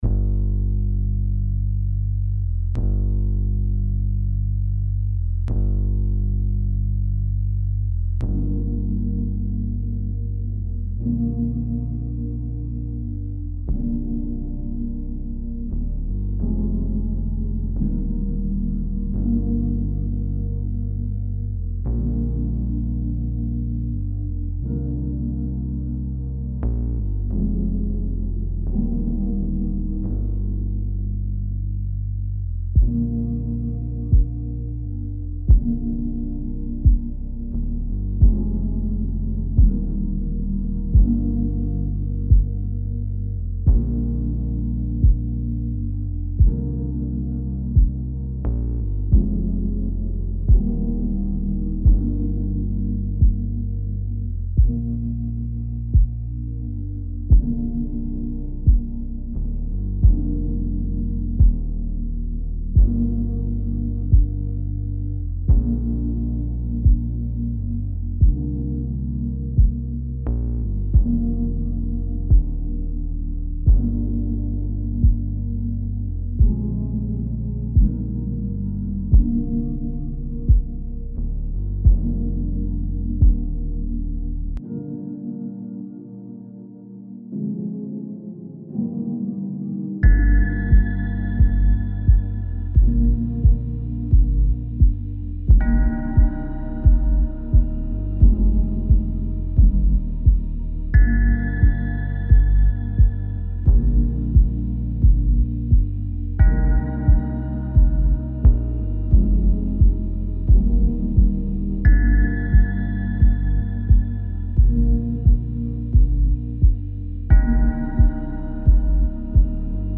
On a décidé de faire des musiques ambiantes qui pourraient coller avec un court métrage ou bien un film.